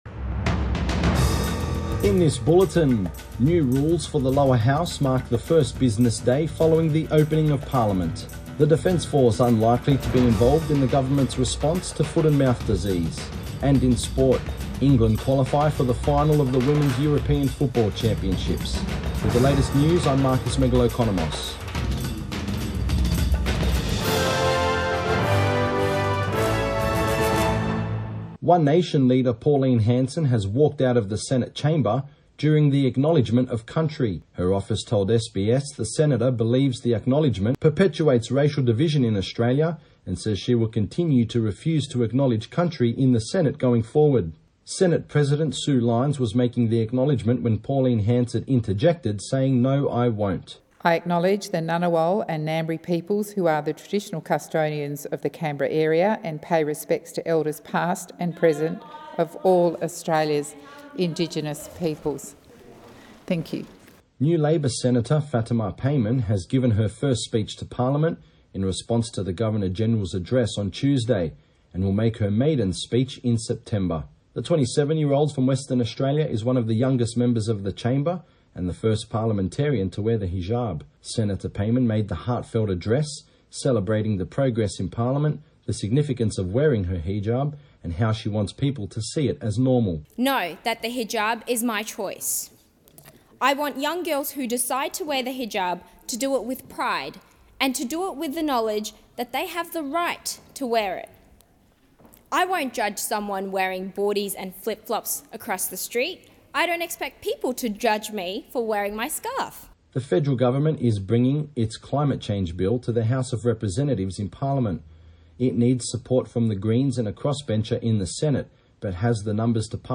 Midday bulletin 27 July 2022